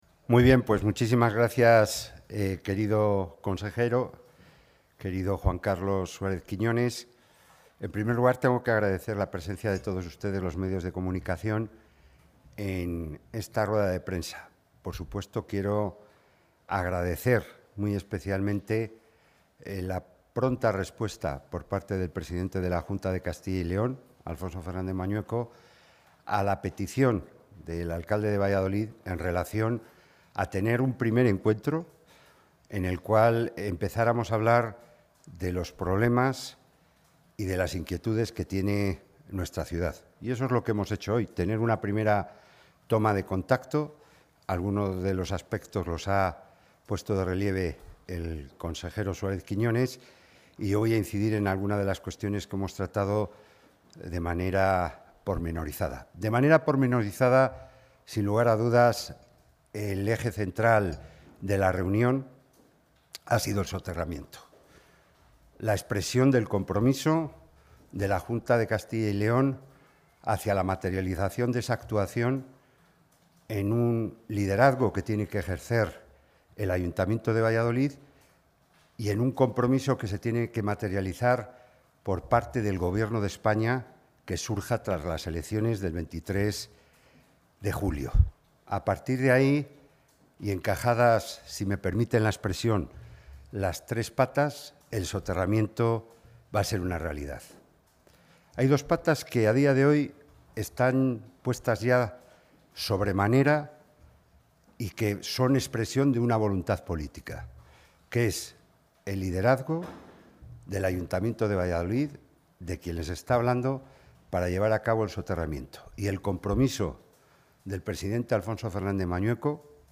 Intervención consejero Medio Ambiente, Vivienda y O. del Territorio.